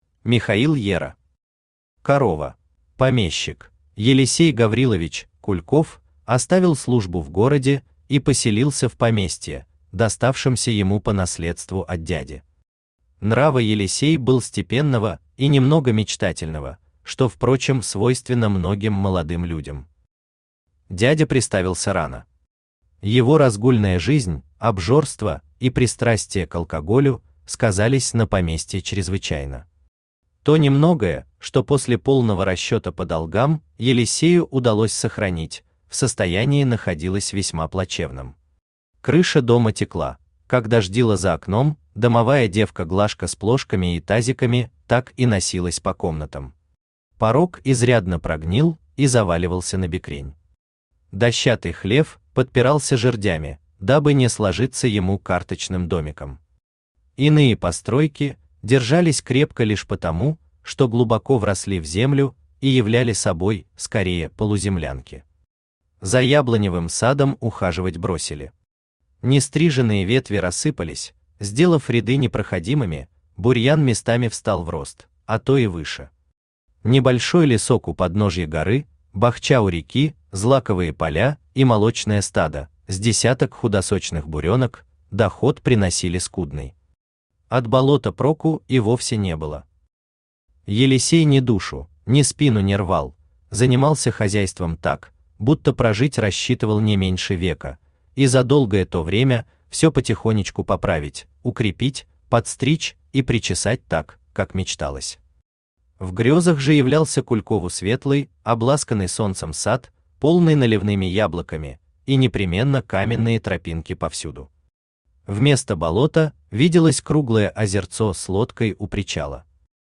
Аудиокнига Корова | Библиотека аудиокниг
Aудиокнига Корова Автор Михаил Ера Читает аудиокнигу Авточтец ЛитРес.